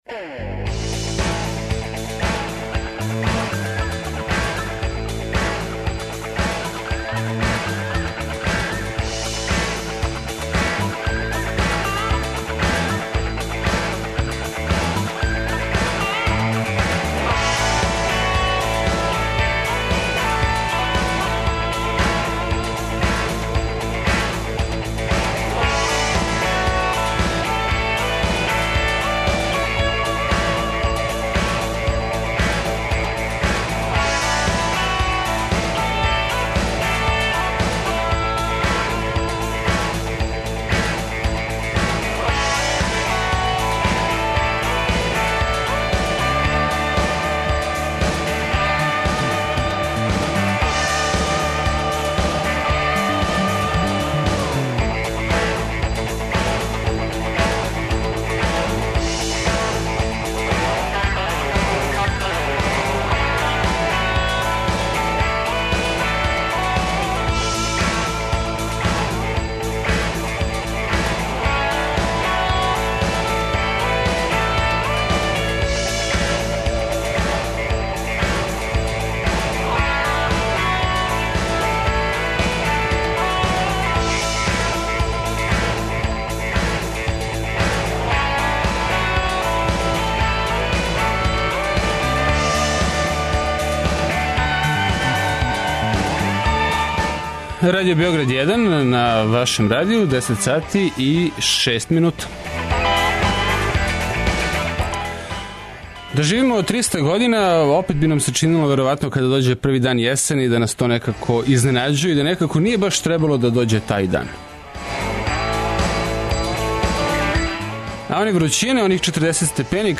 Од 10 до 11 часова разговараћемо у три кратка интервјуа из студија о друштвеним актуелностима, док после 11 часова емитујемо програм уживо са Трга Николе Пашића где ће бити у току одбојкашки дан.
У нашем монтажном студију на три метра висине (колико је потребно да би се коментарисала утакмица) попеће се и бројни саговорници из света спорта.